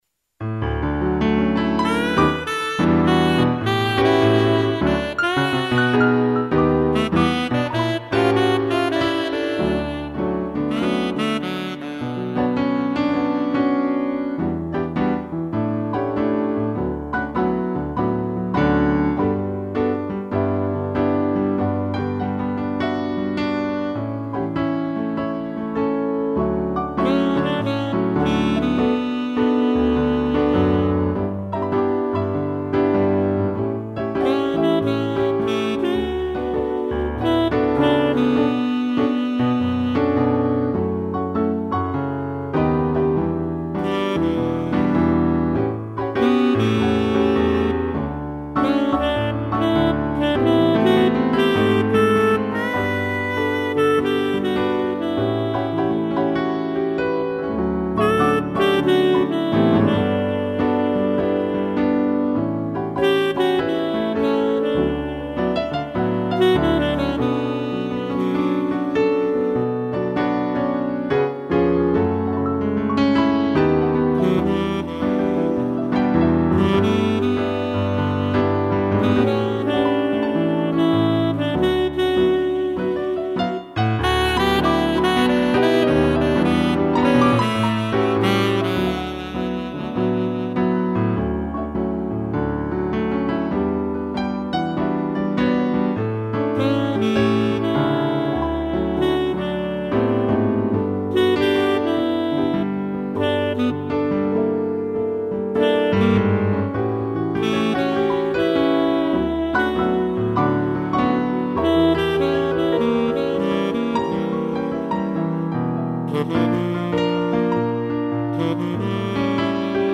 2 pianos e sax
instrumental